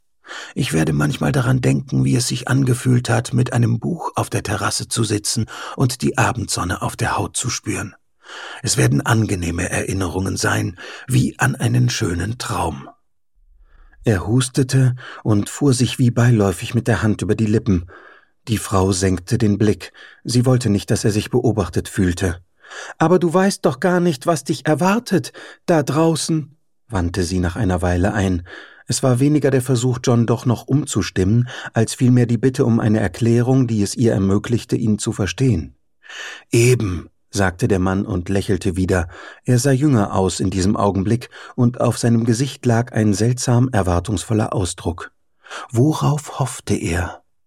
Mittel plus (35-65)
Audiobook (Hörbuch)